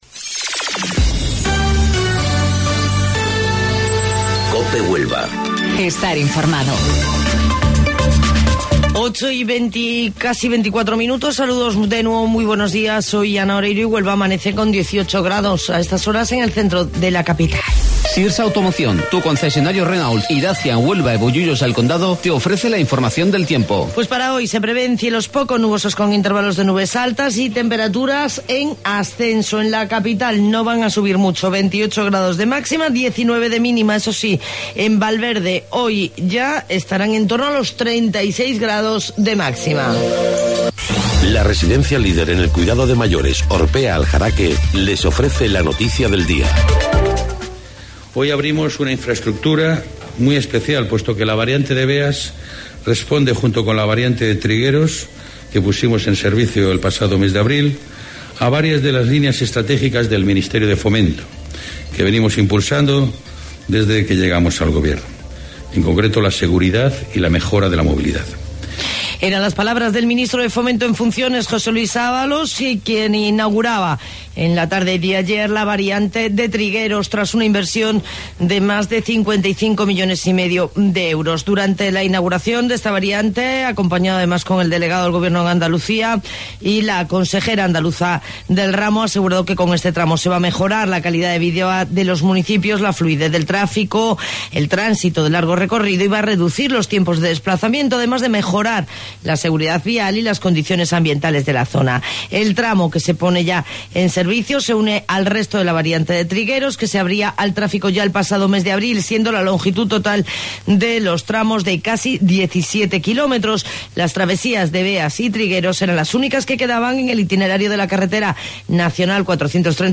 AUDIO: Informativo Local 08:25 del 10 de Julio